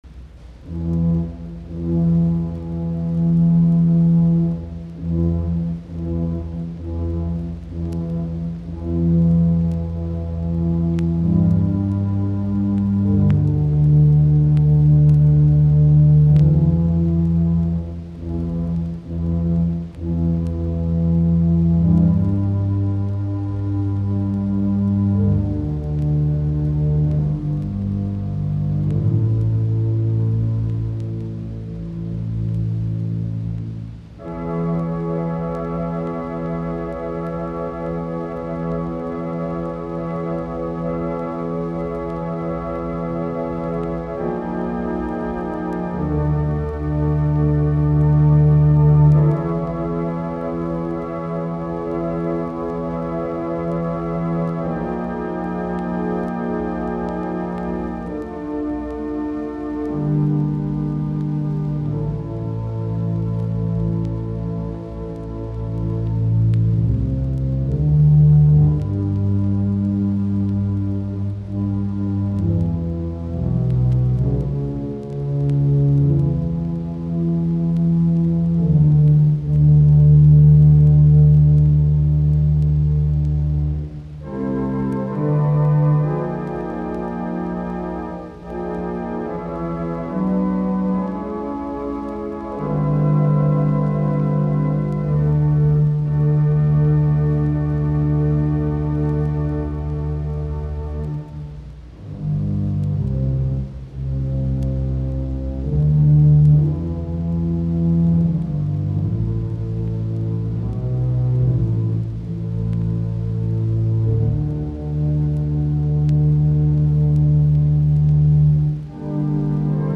Soitinnus: Kuoro, urut.